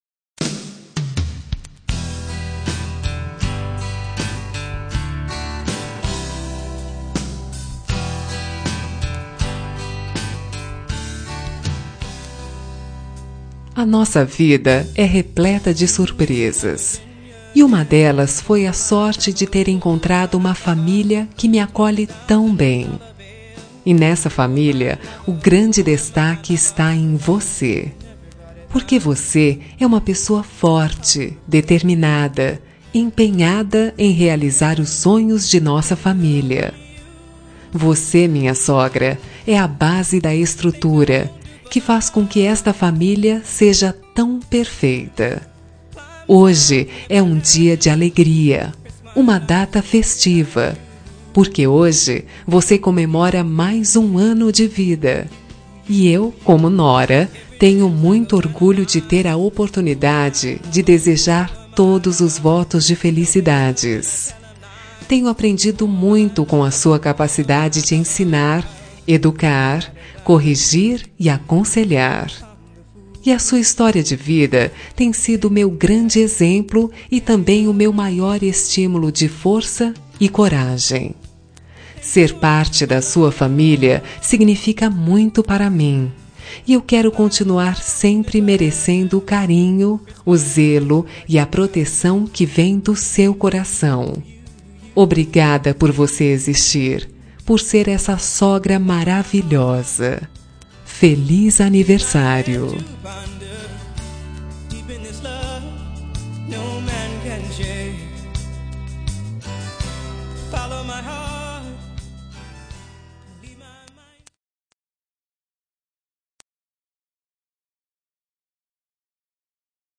Telemensagem de Aniversário de Sogra – Voz Feminina – Cód: 1964